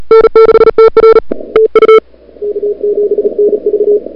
CW Sprint QSO Example
These are actual, off the air, QSOs.